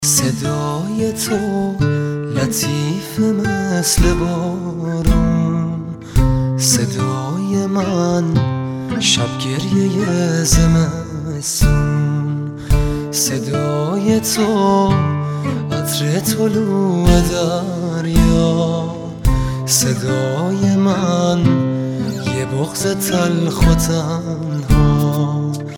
رینگتون زیبا و احساسی